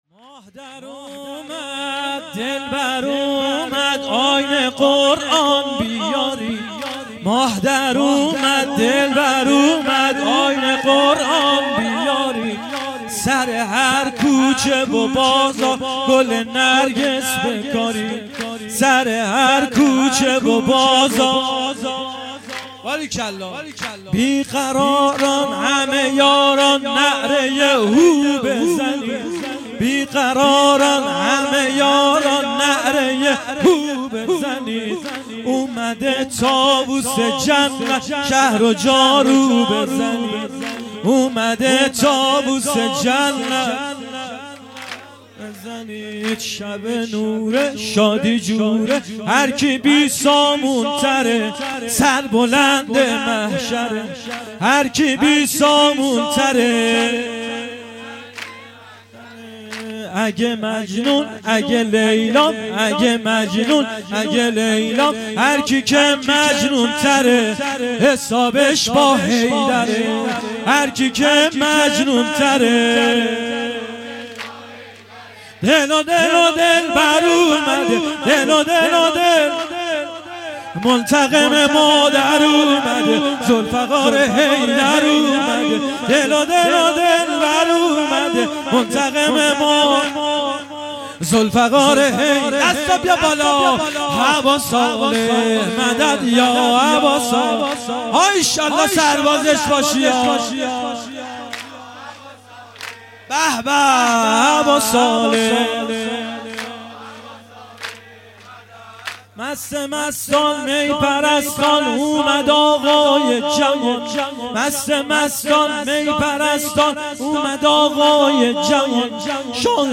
سرود | ماه در امد گل در امد